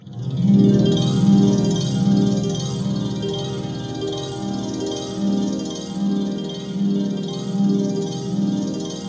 ATMOPAD02 -LR.wav